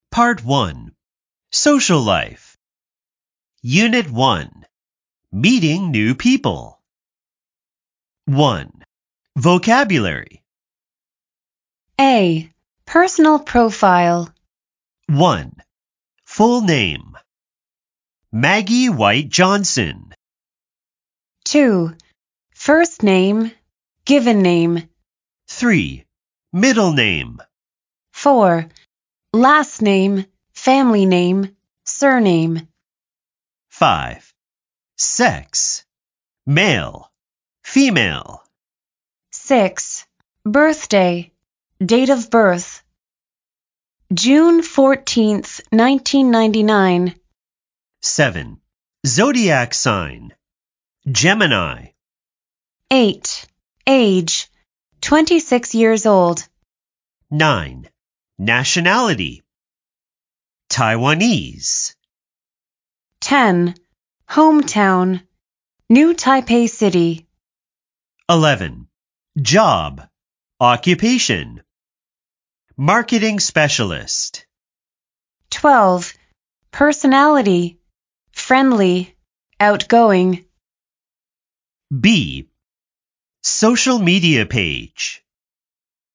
掃描書封QR Code下載「寂天雲」App，即能下載全書音檔，無論何時何地都能輕鬆聽取專業母語老師的正確道地示範發音，訓練您的聽力。